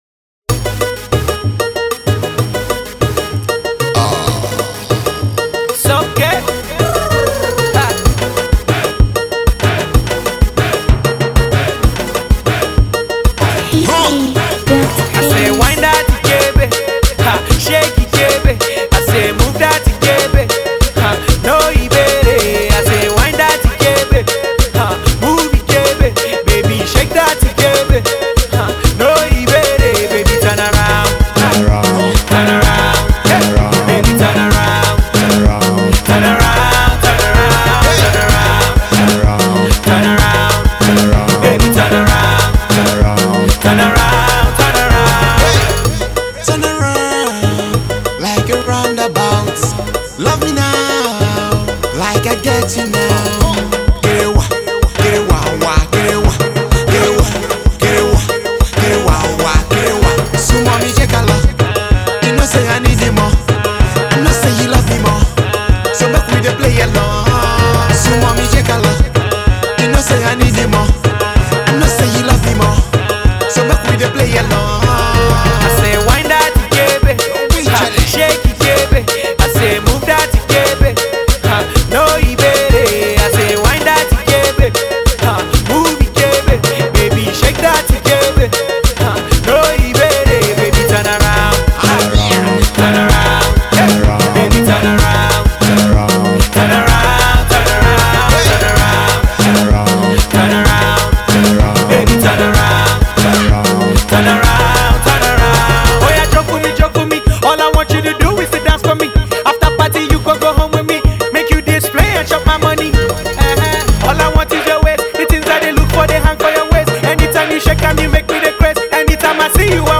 Pop …